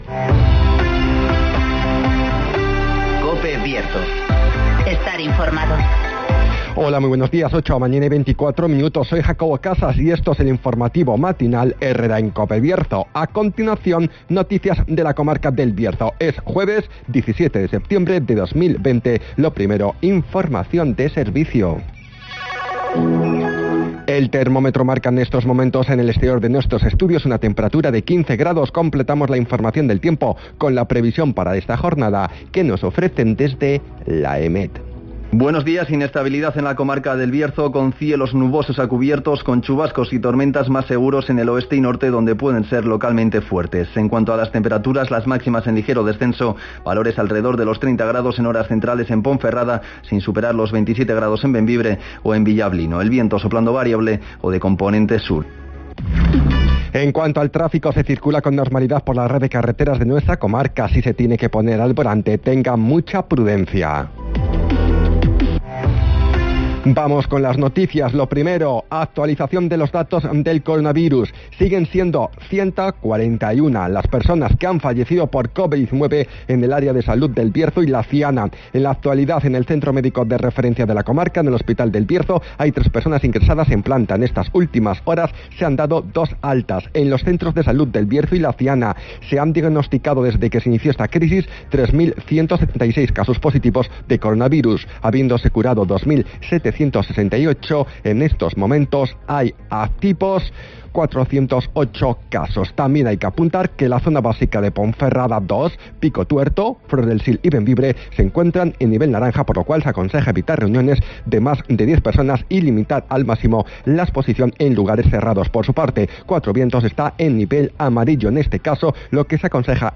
INFORMATIVOS
Conocemos las noticias de las últimas horas de nuestra comarca, con las voces de los protagonistas
-Palabras de Marco Morala, portavoz del PP, y Olegario Ramón, alcalde de la capital berciana